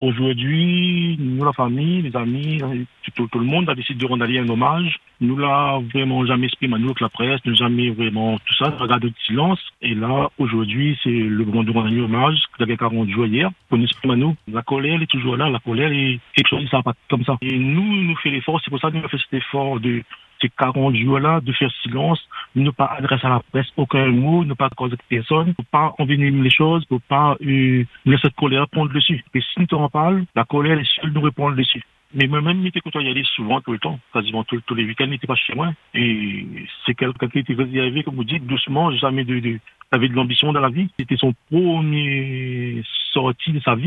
avec émotion et dignité